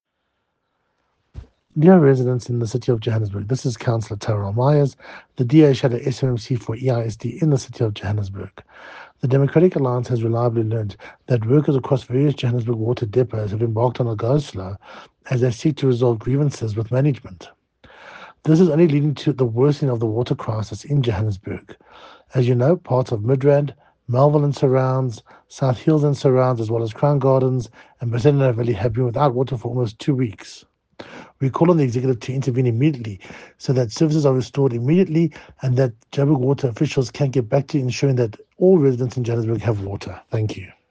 English soundbite by Cllr Tyrell Meyers